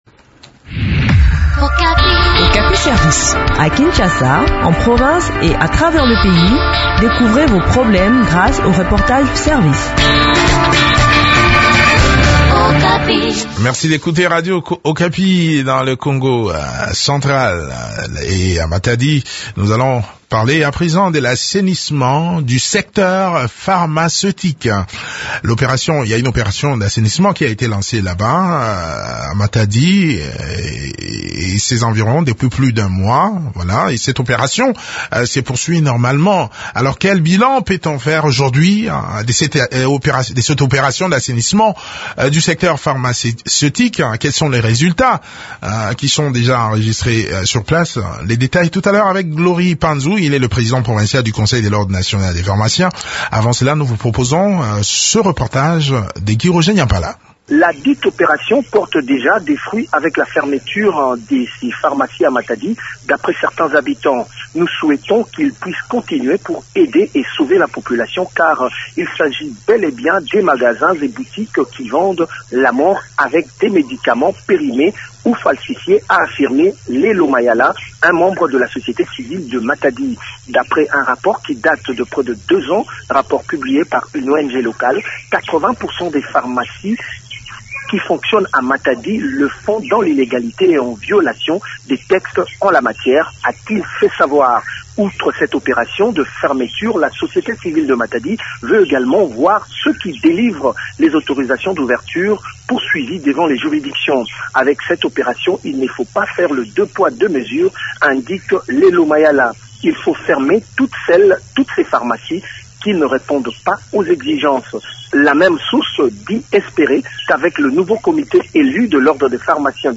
Le point sur le déroulement de l’opération dans cet entretien